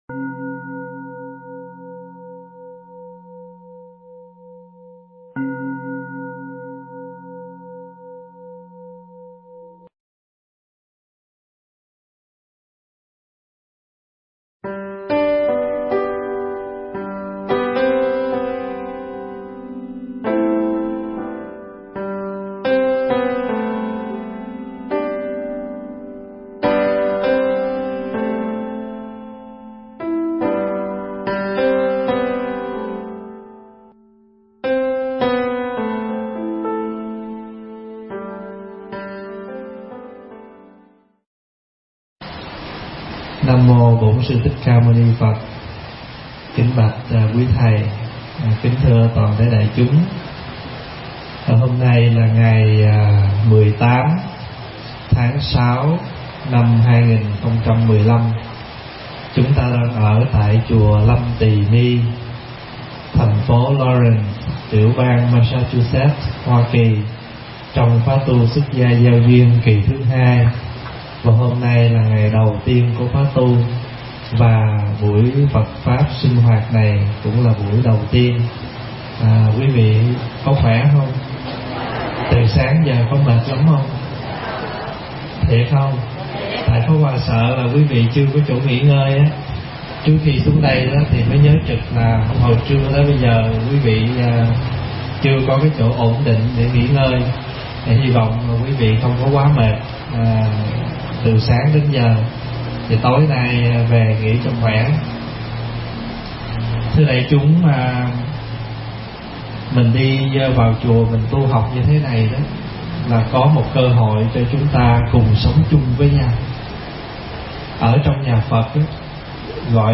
thuyết giảng tại Chùa Lâm Tỳ Ni trong khóa xuất gia gieo duyên